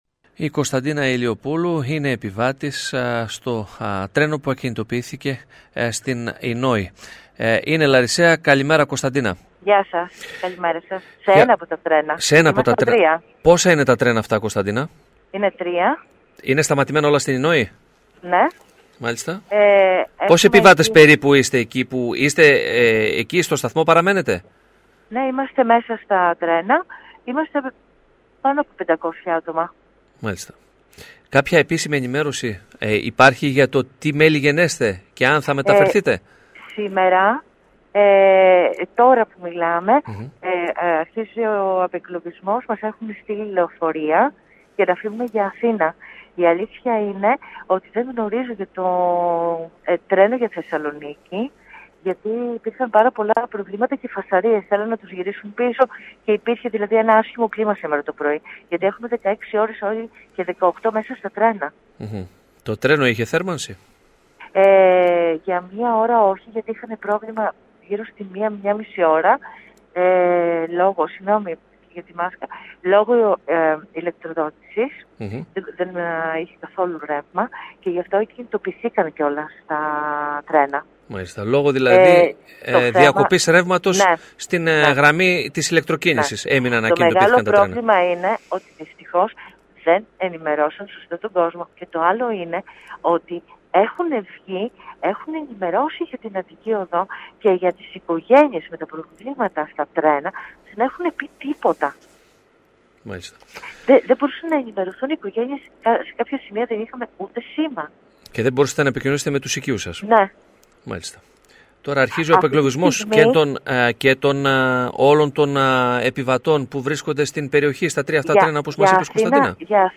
Οινόη: Mεταφέρθηκαν με λεωφορεία οι εγκλωβισμένοι σε δύο αμαξοστοιχίες – Επιβάτης μιλάει στην ΕΡΤ (video – audio)
Απίστευτη ταλαιπωρία και για τους 800 επιβάτες δύο αμαξοστοιχιών που παρέμεναν εδώ και 17 ώρες εγκλωβισμένοι καθώς ακινητοποιήθηκαν λόγω της κακοκαιρίας στην περιοχή της Οινόης. Κάποιοι μεταφέρθηκαν με λεωφορεία προς Αθήνα Επικοινωνία με Λαρισαία επιβάτιδα, που παρέμεινε στην αμαξοστοιχία 55 από χθες το πρωί στις 10.30 μέχρι πριν από λίγο είχε η ΕΡΤ Λάρισας.